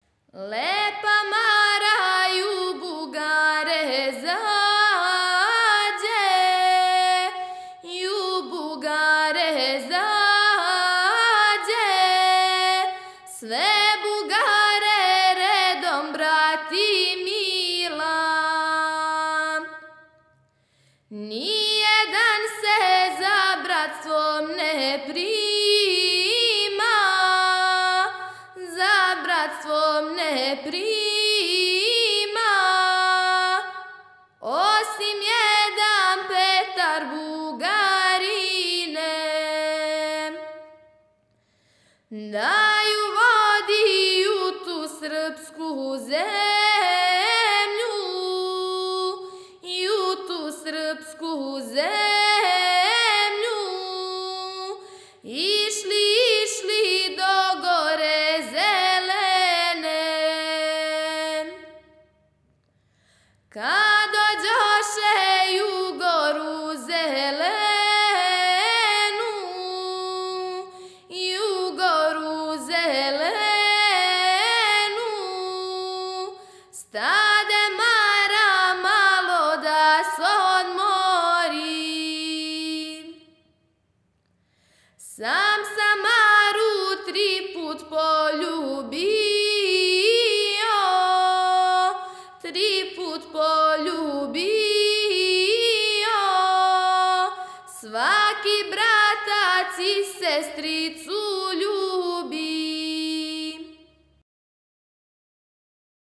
Порекло песме: Североисточна Србија Начин певања: ? Напомена: Балада на бас из околине Неготина.